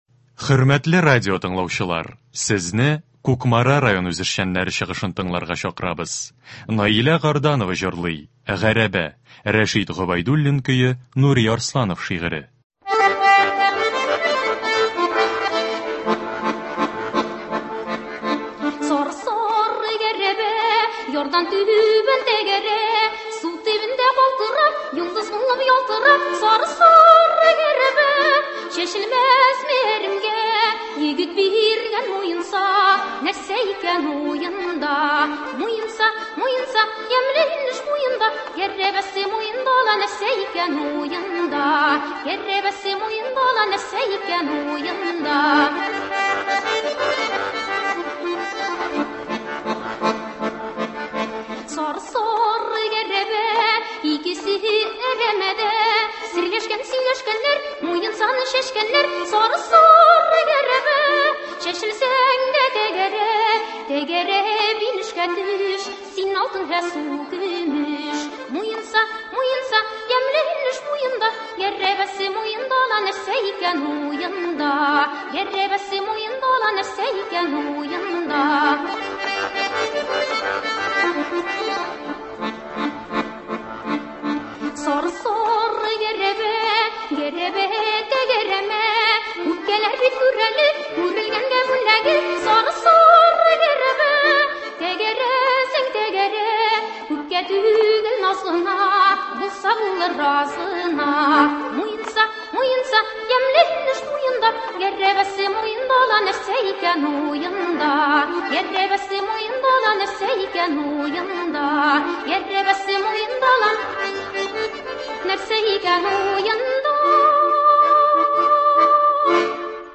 Концерт. 2 февраль.